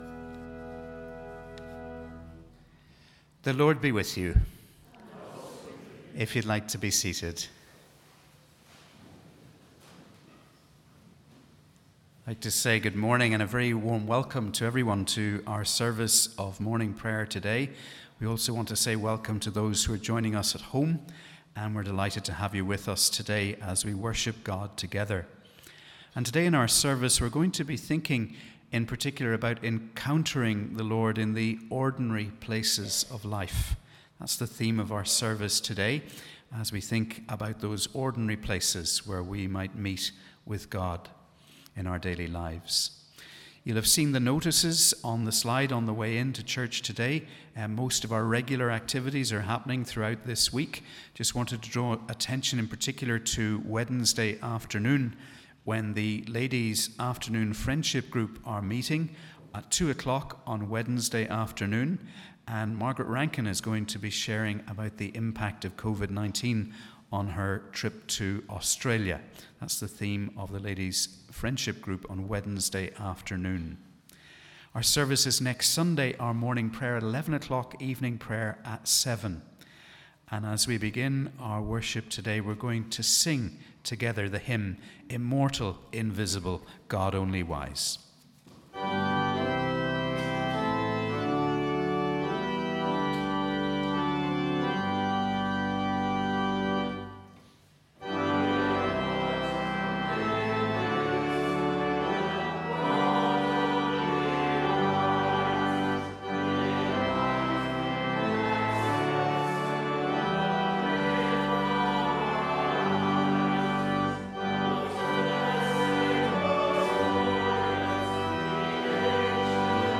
We warmly welcome you to our service for the 4th Sunday before Lent.